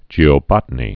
(jēō-bŏtn-ē)